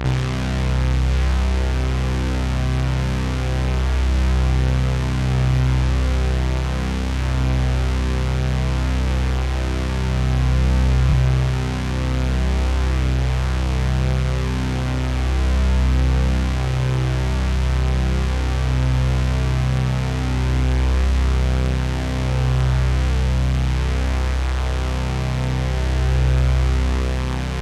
Bass (IGOR'S THEME).wav